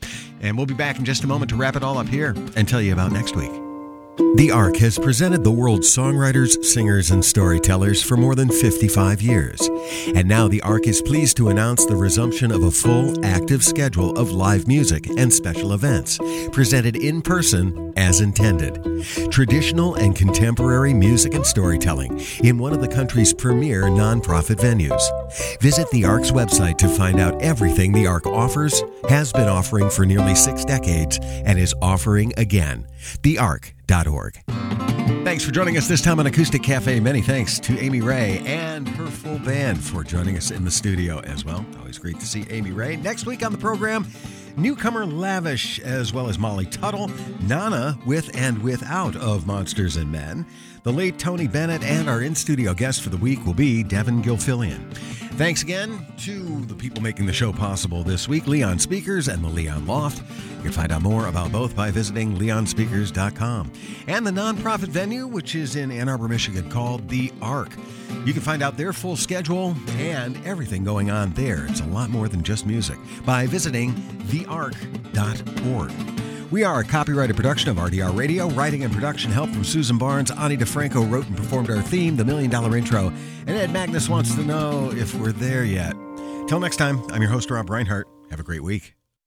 (webstream capture)